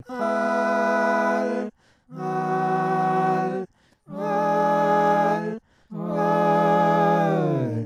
disorientvox.wav